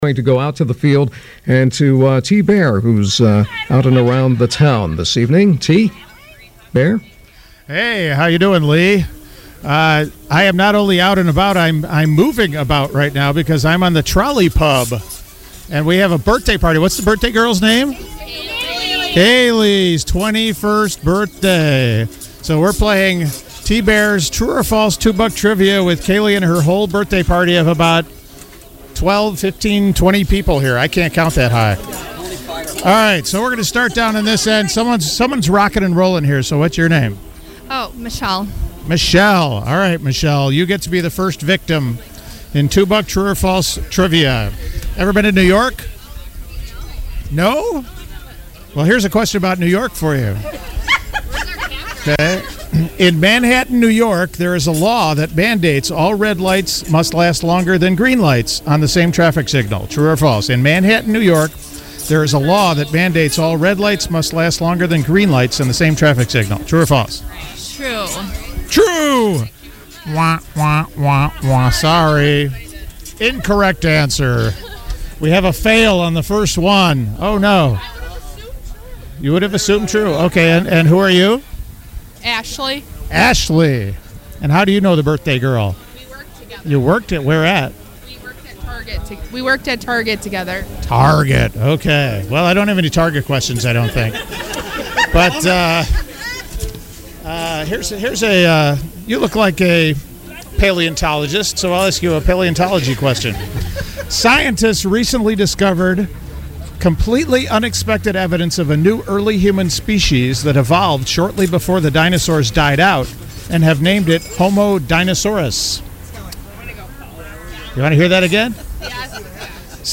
Old (and we mean old!) DJ’s recreate their shows from days gone by and take to the streets to force innocent people into taking part in contests.
He’s using an iPhone X and an iRig HD2 microphone.